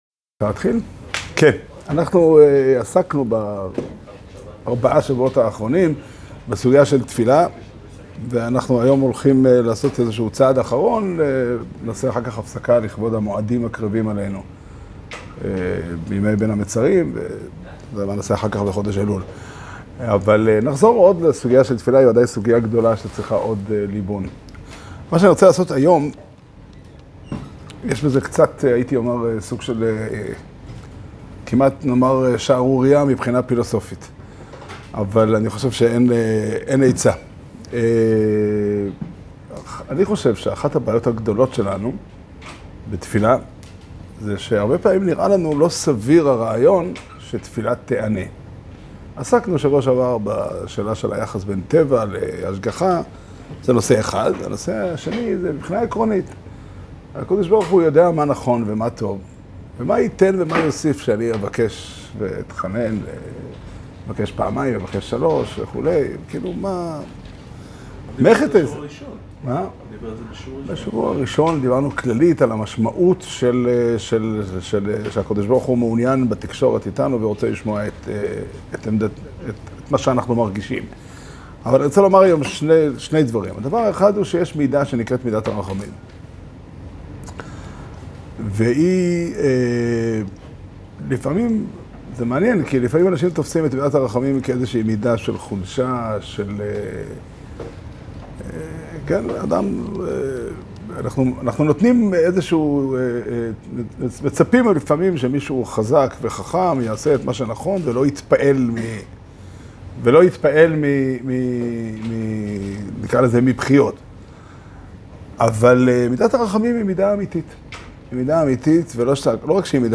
שיעור שנמסר בבית המדרש פתחי עולם בתאריך י"ב תמוז תשפ"ד